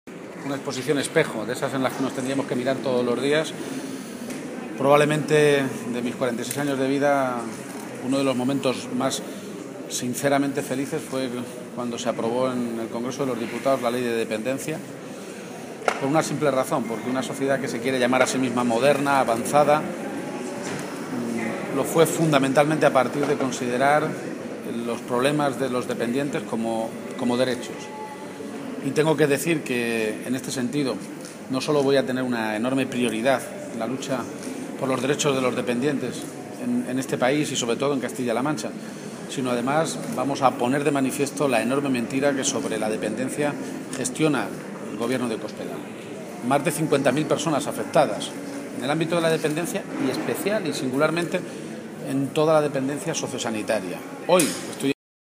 Lo hacía mientras atendía a los medios de comunicación, minutos antes de la inauguración, en la Casa de la Cultura del municipio azudense, de la exposición, «La Lucha por la Dignidad».